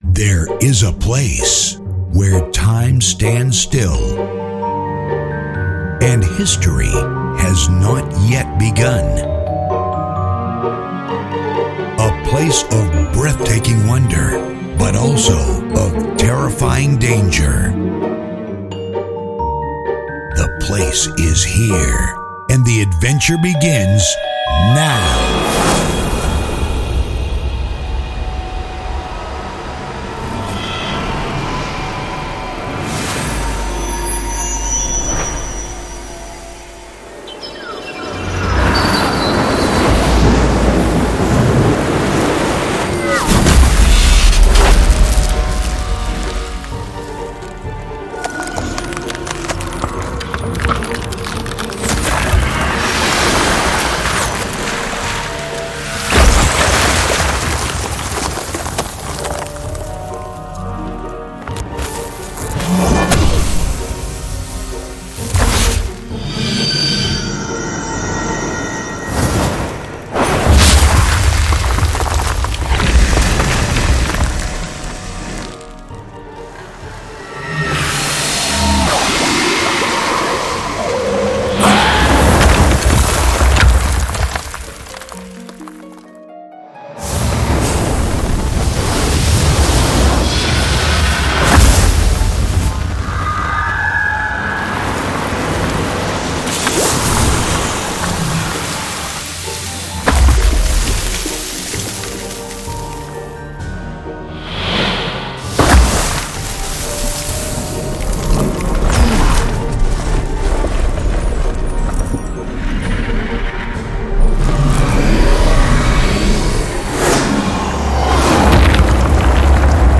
它具有超过13 GB的元素暗魔法SFX，包括+1,750种与咒语，诅咒，魔术，探索，防御，转换，攻击等有关的高品质音效。
这个迷人的图书馆专注于魔术的7个主要元素：酸，地球，电，火，冰，自然和水，还包括大量其他材料，可让您满足图书馆的需要-并为您带来生产-就绪的声音效果，例如：献祭，瘫痪，折磨，石化，召唤，酸刺，冰川尖峰，能量爆发，火力爆发，暴风雨，冰冻的触摸，沙尘暴，水龙卷，射箭，灼烧，巨石投掷，心灵感应，点火，暴风雪咒语，水击，复仇咒语，酸雨等等。
加上完全有机元素的含量，例如电，火，冰，液体，岩石，沙砾和鹅卵石，风和树林。
奖励的声音包括氛围，怪兽和生物SFX，毒刺和嘘声。
所有声音均使用Rode NTG-3，Rode NT4，Rode NTG-8，Rode NT1，AKG C414，声音设备722，Zoom F8，Zoom H6，Tascam DR-44WL录制，并经过完美分类和标记，以使您轻松使用使用它们并准备与其他音源结合，为您的制作创造最独特的声音。